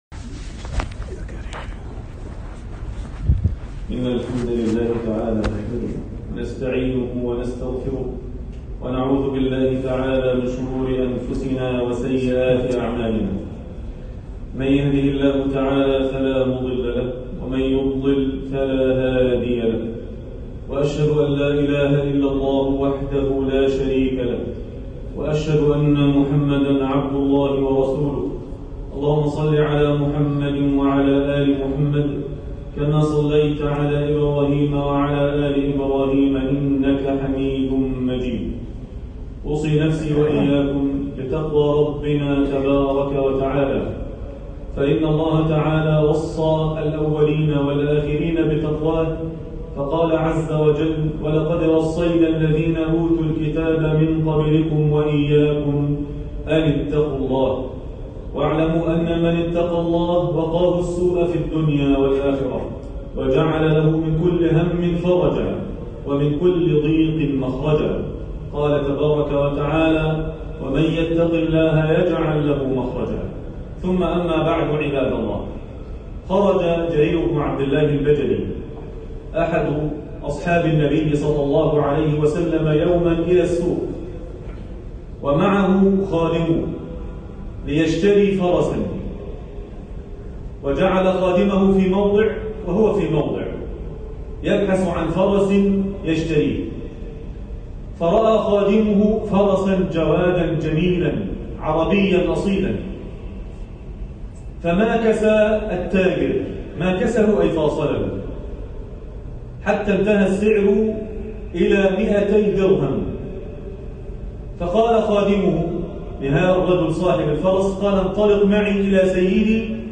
من غشنا فليس منا - خطبة الجمعة